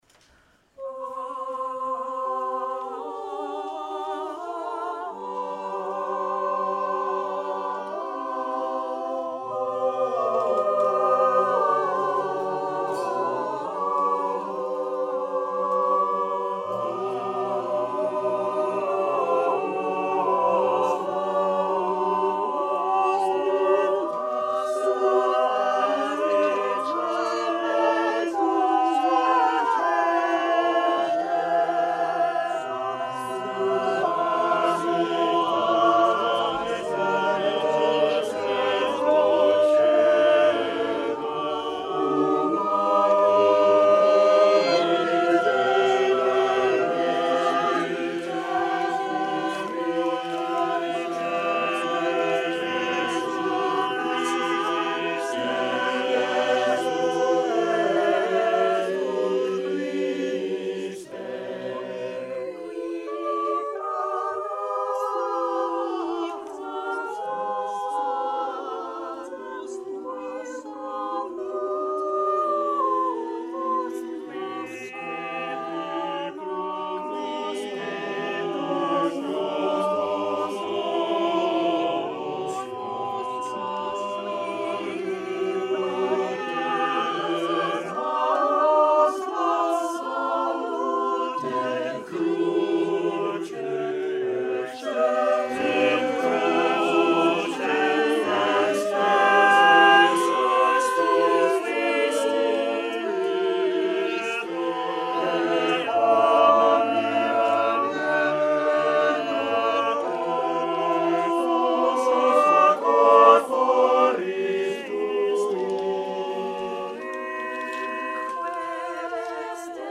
The Renaissance Street Singers' 45th-Anniversary Loft Concert, 2018